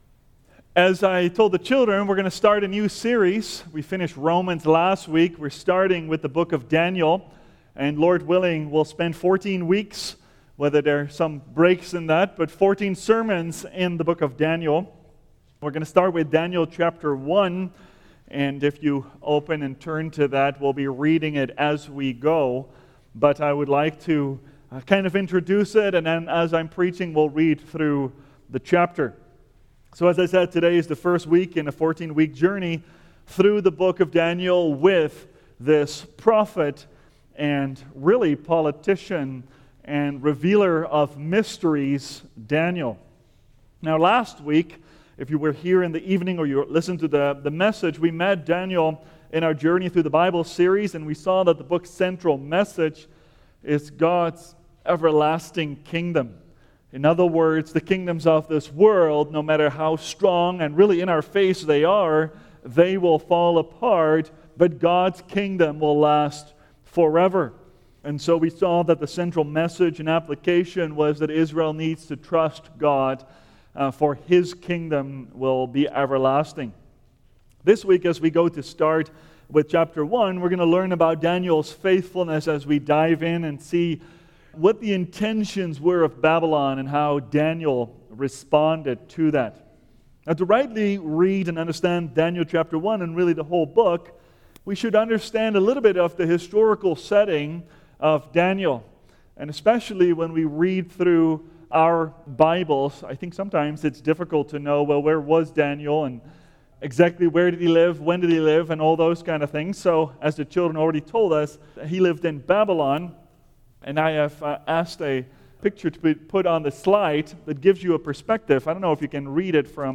Faithfulness Series The Book of Daniel Book Daniel Watch Listen Save Using Daniel 1:1-21, we begin a series on the book of Daniel highlighting the kingdom of God, the Son of Man, and the things to come. In this first sermon, Daniel’s faithfulness to God is tested and rewarded by God.